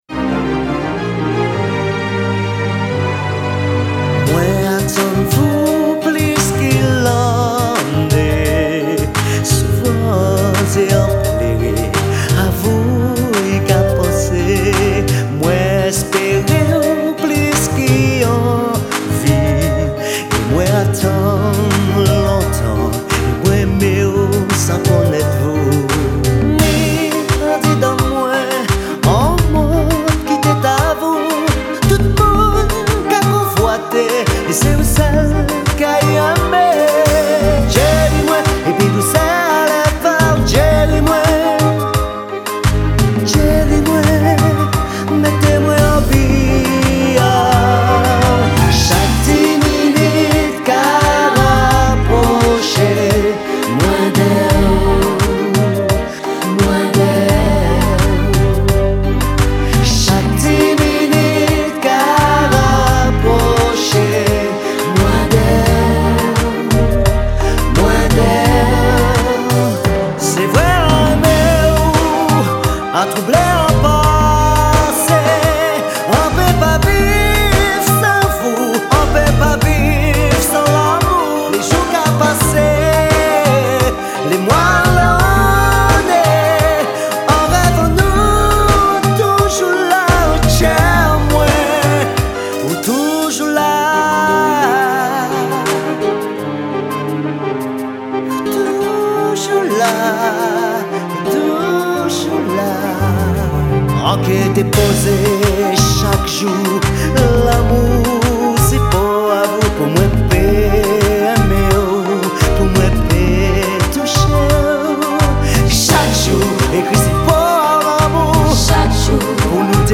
Genre : zouka.